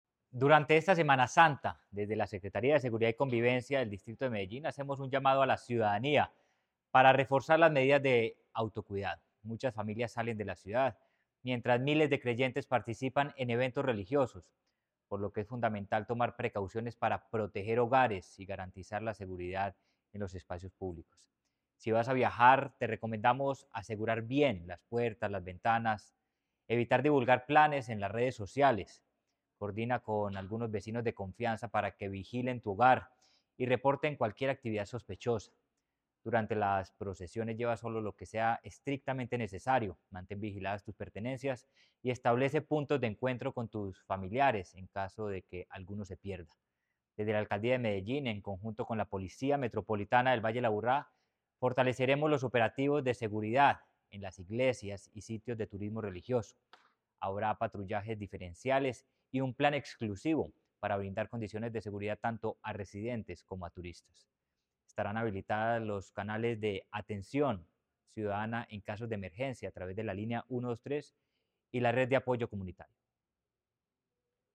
Declaraciones secretario de Seguridad y Convivencia, Manuel Villa Mejía.
Declaraciones-secretario-de-Seguridad-y-Convivencia-Manuel-Villa-Mejia.-1.mp3